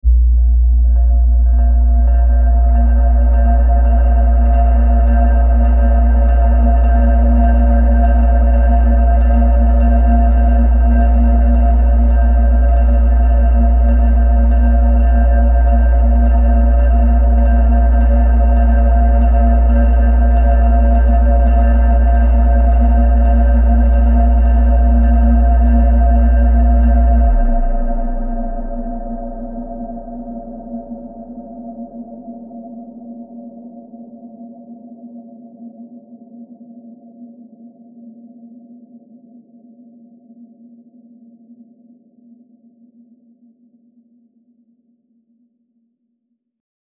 Атмосфера мрачной крепости в ночные часы